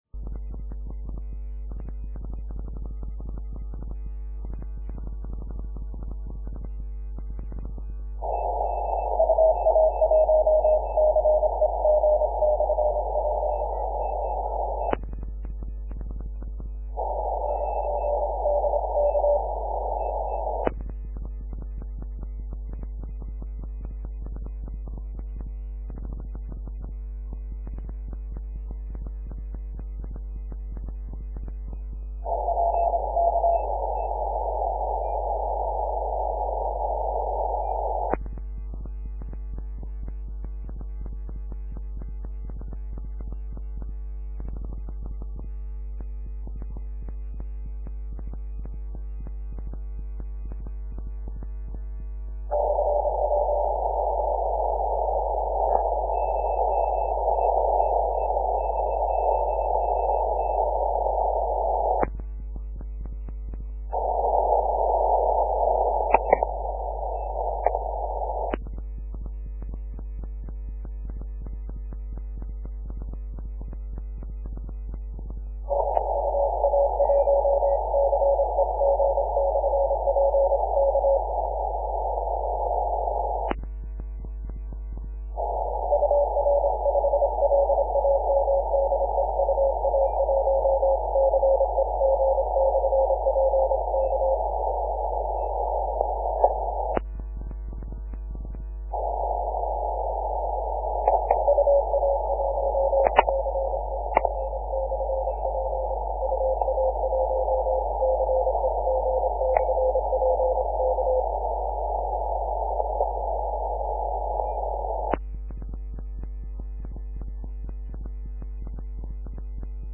Помех тогда было навалом! Можете послушать, не так уж плохо сигнал моего радио и проходил.